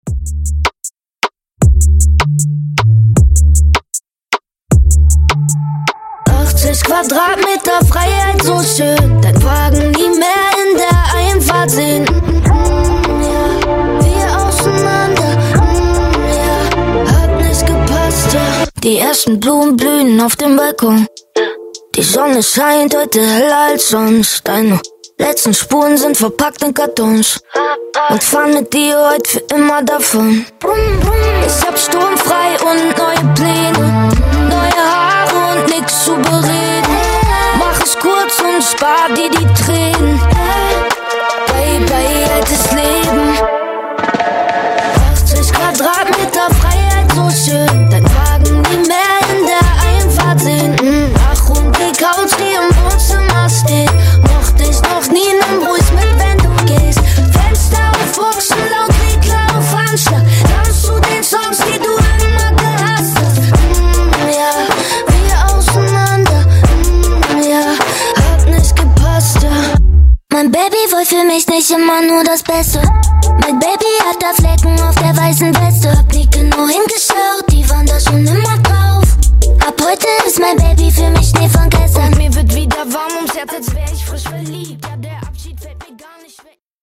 Genre: RE-DRUM
Dirty BPM: 160 Time